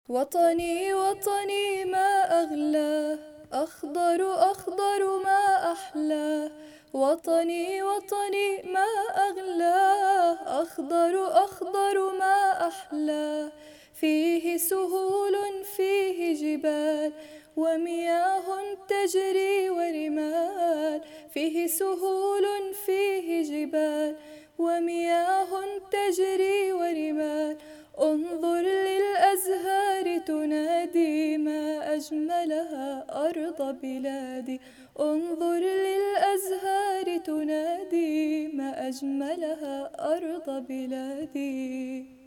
انشد وطني صف اول فصل اول منهاج اردني